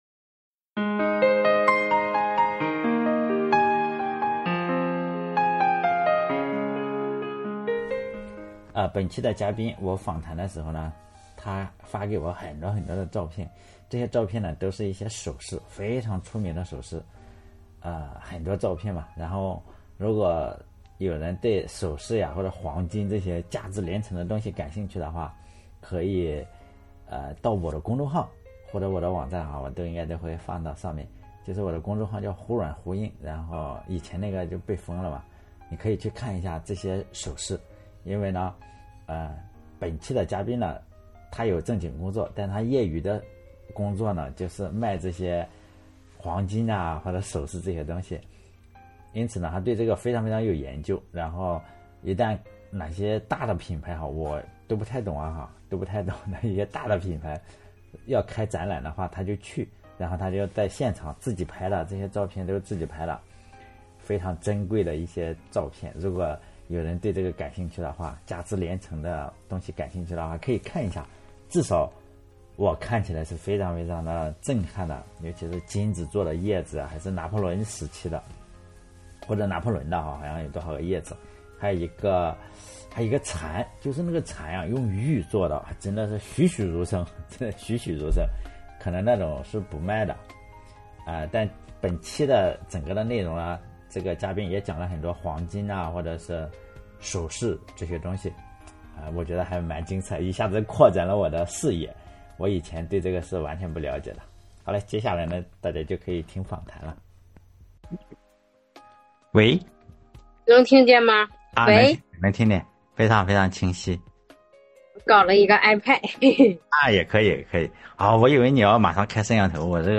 本期访谈的嘉宾是一位像悟空一样活泼可爱的姑娘